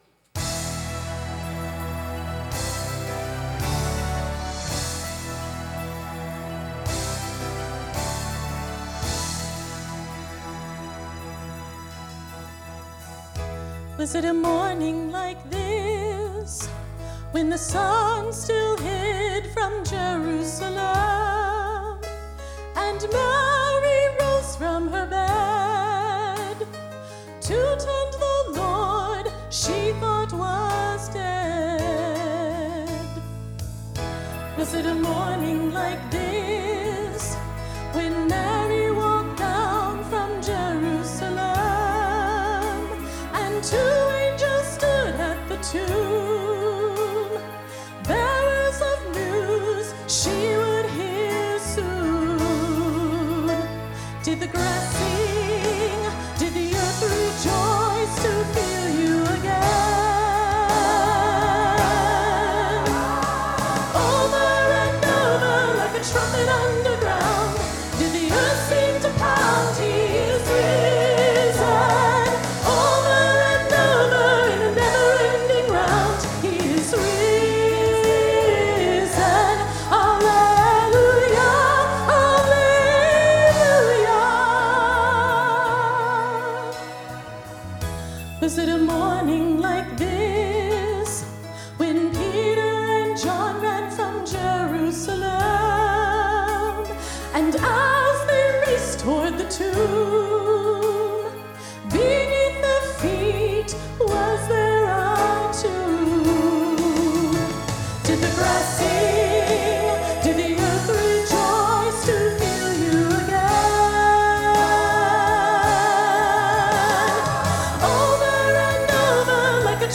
Sunday Morning Music
Solo - Was It a Morning Like This